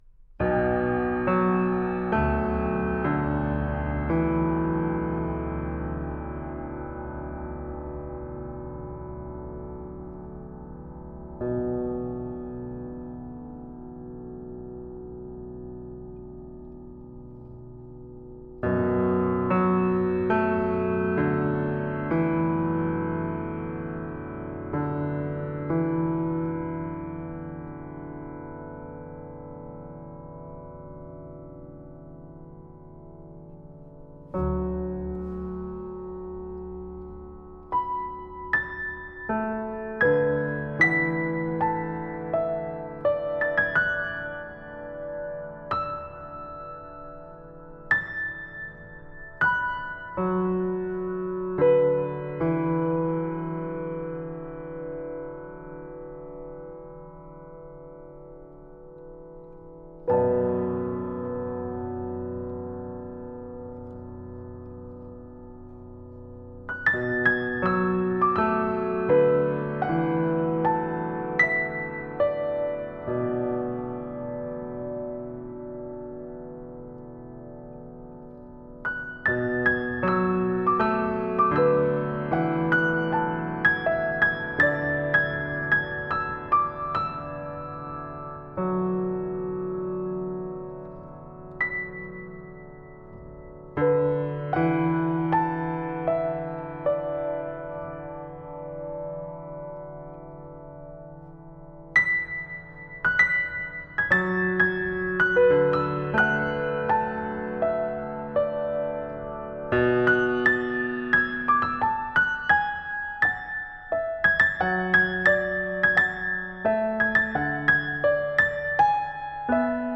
4. Genre: New classical
The Dedication is my first — and only — piano four hands composition. Its melancholic mood emerges from its minor key (A melodic minor, mode 2), its slow tempo, and, its copious use of space.
Recorded Sunday, 30 January 2011 at Queens College.